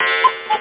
1 channel
CUCKOO.mp3